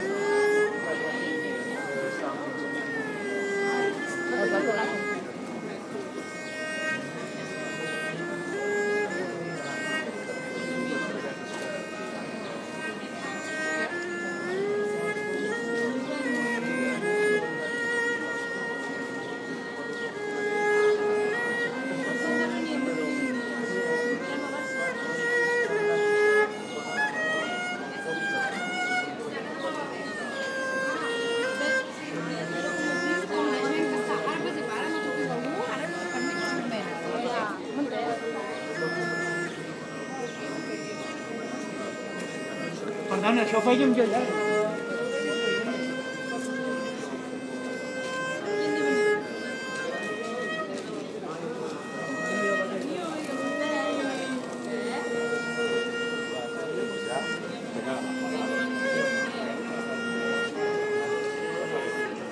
Violinista chino callejero Girona